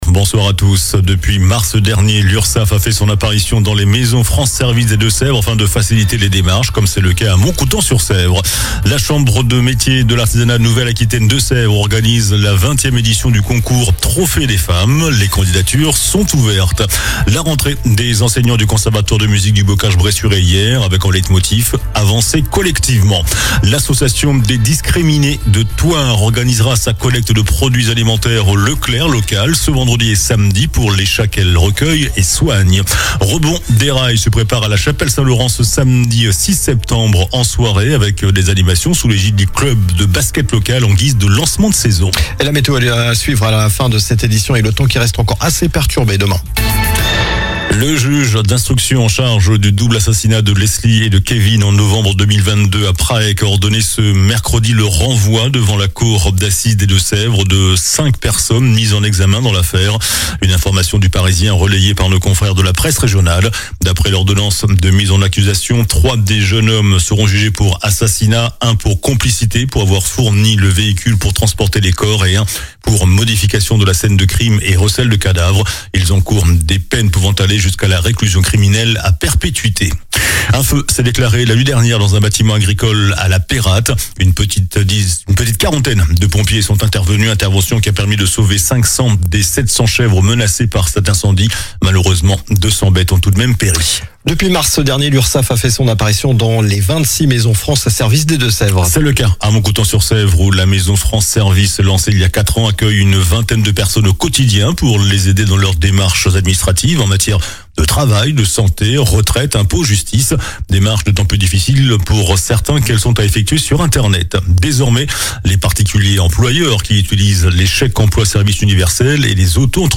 JOURNAL DU MERCREDI 03 SEPTEMBRE ( SOIR )